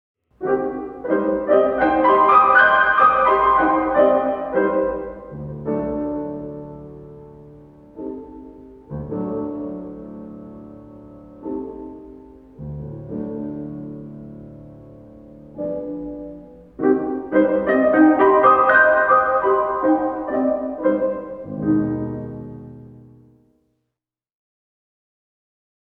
groep5_les1-5-2_geluiddieren1.mp3